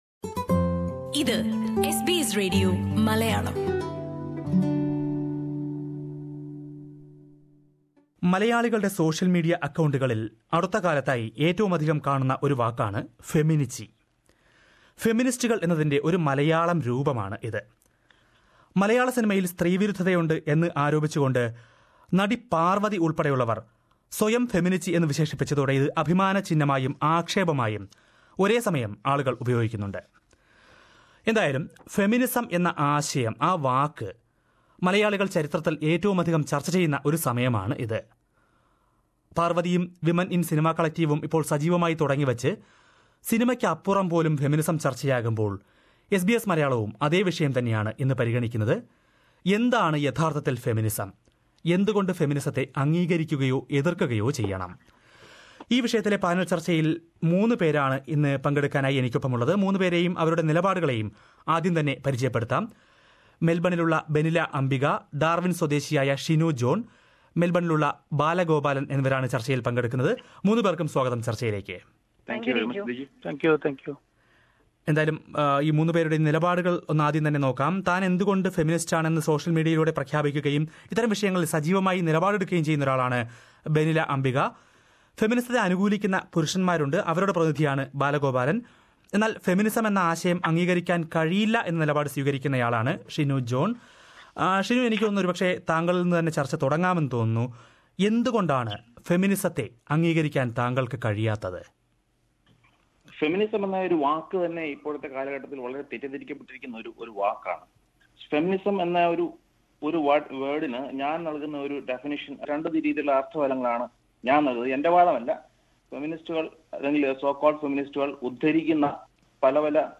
Is feminism good or bad? Malayalees on social media are busy discussing this, following the recent developments in the Malayalam film industry. SBS Malayalam has conducted a panel discussion on this subject.